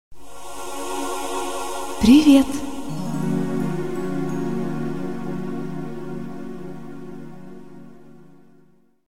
Звук женского голоса, который говорит привет (на фоне мелодия)
• Категория: Привет(приветствие)
• Качество: Высокое